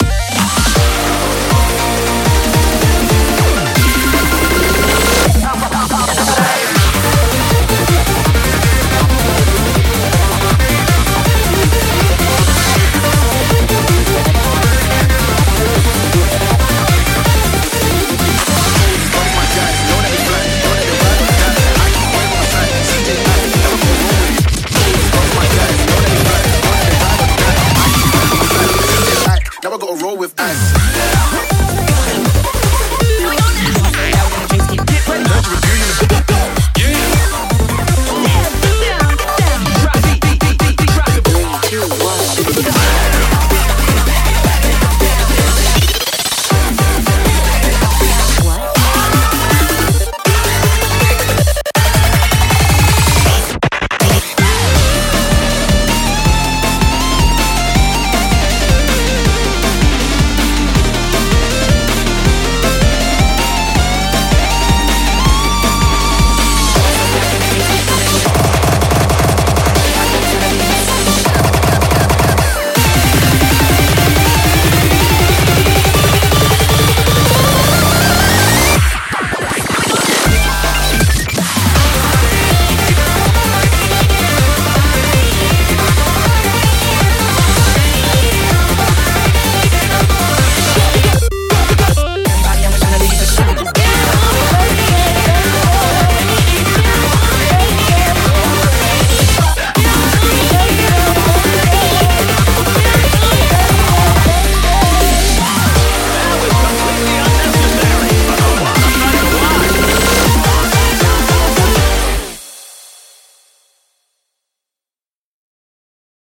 BPM160
Audio QualityPerfect (High Quality)
BREAKCORE